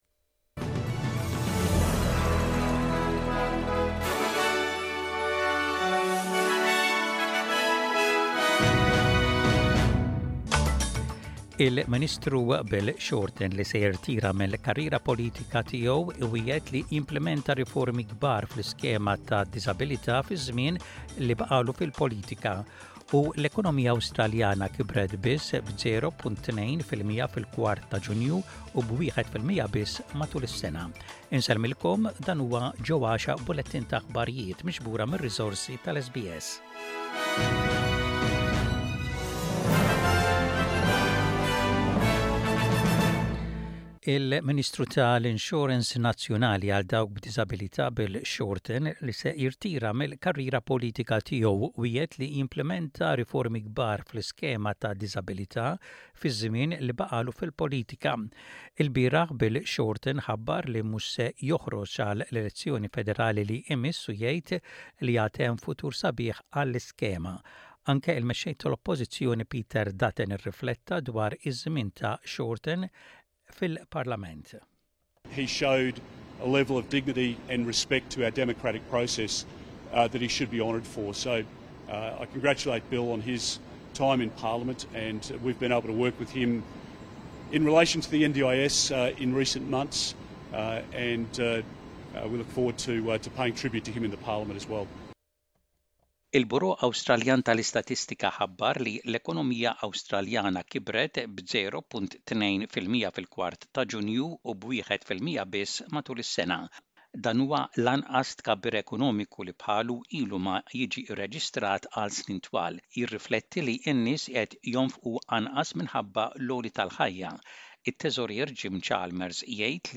SBS Radio | Aħbarijiet bil-Malti: 06.09.24